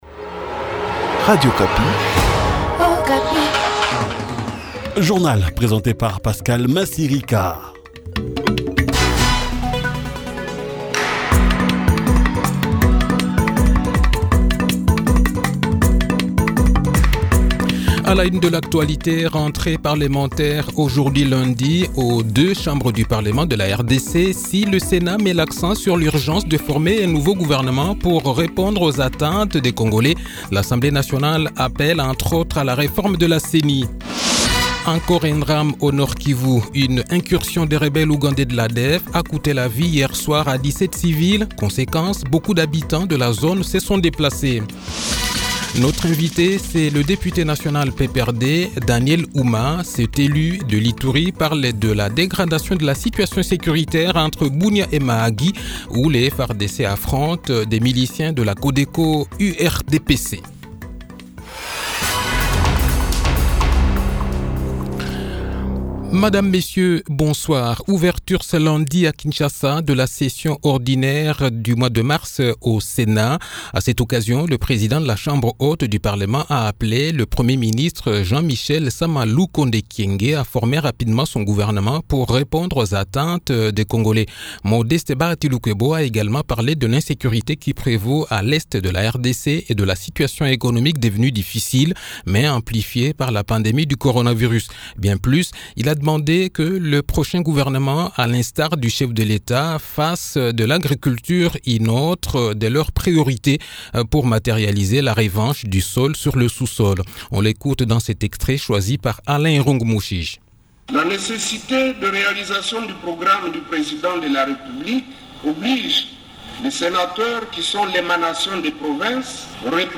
Le journal de 18 h, 15 Mars 2021